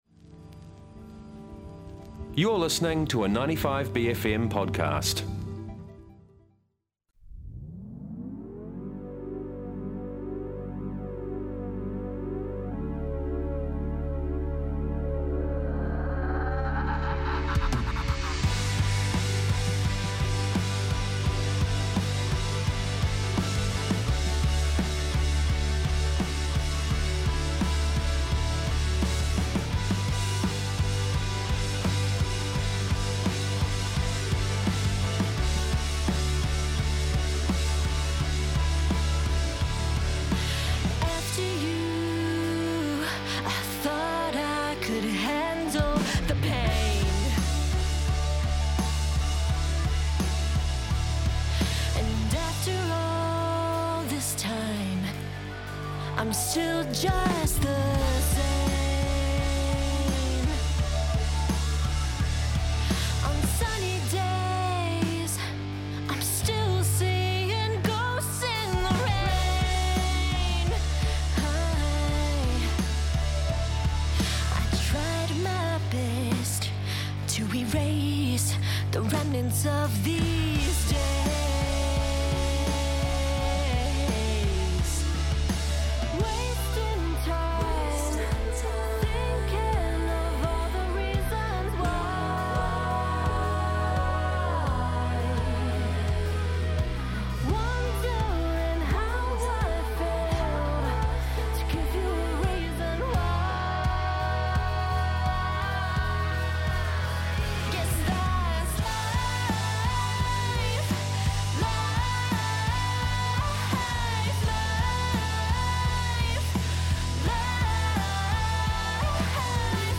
Interview with Club Ruby 3 February 2026.mp3